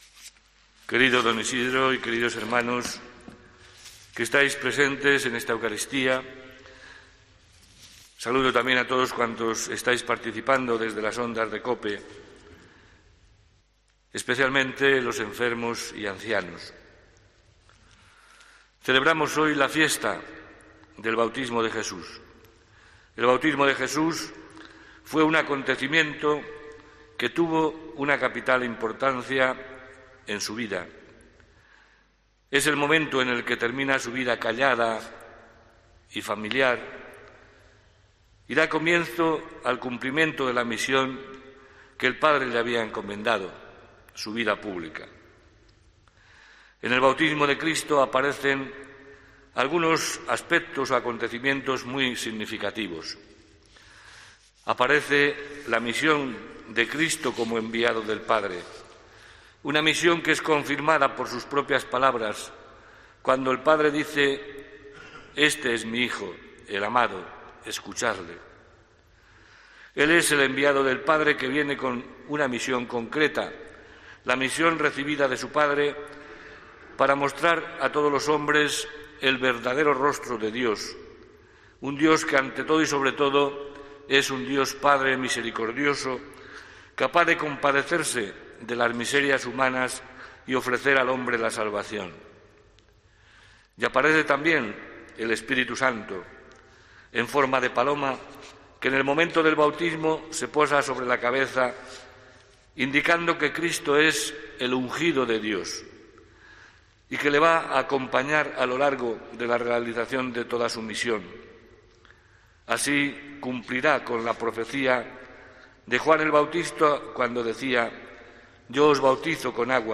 HOMILÍA 10 ENERO 2021